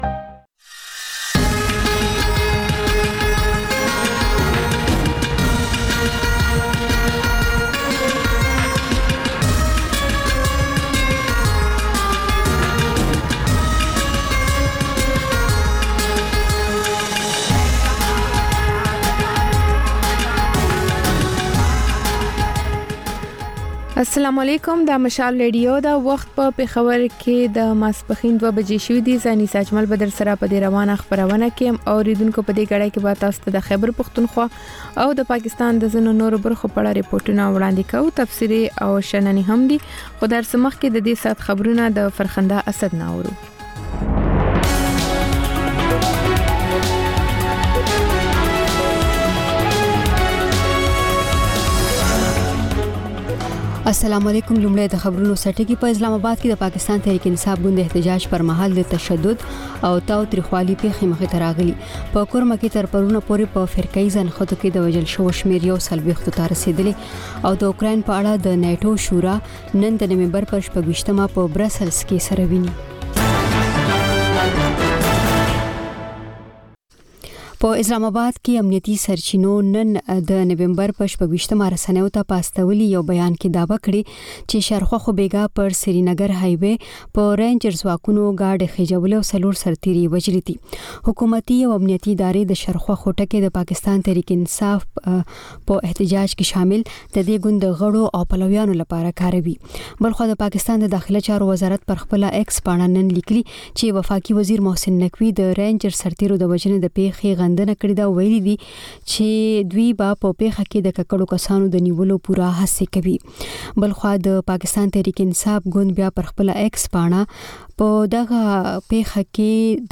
په دې خپرونه کې لومړی خبرونه او بیا ځانګړې خپرونې خپرېږي.